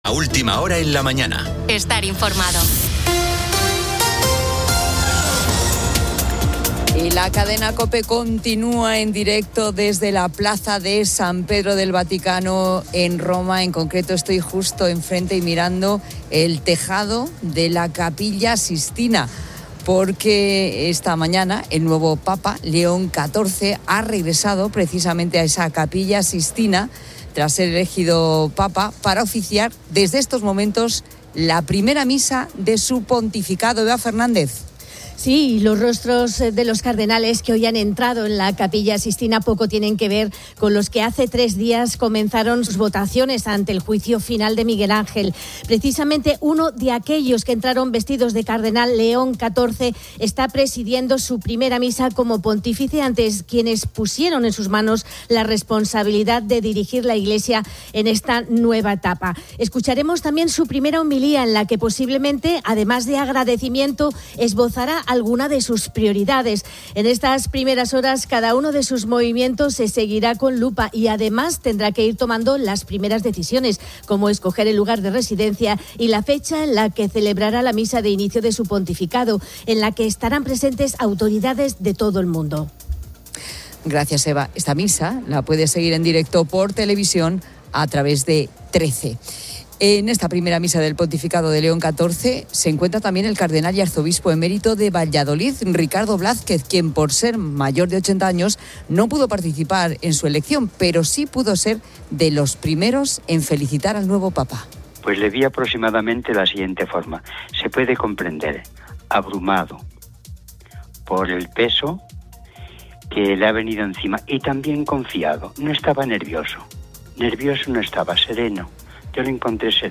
Y la cadena Cope continúa en directo desde la Plaza de San Pedro del Vaticano en Roma, en concreto...